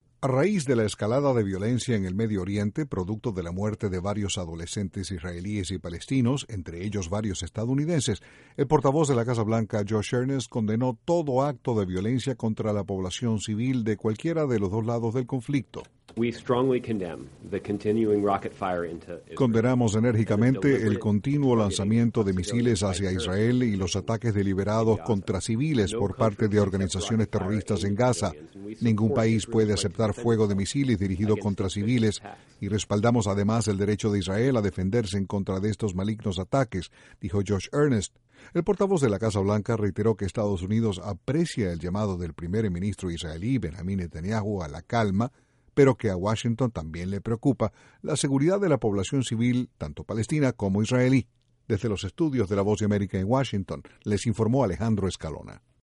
INTRO: En medio de la crisis de violencia en el Medio Oriente, la Casa Blanca reiteró el derecho que Israel tiene a defenderse. Desde la Voz de América, en Washington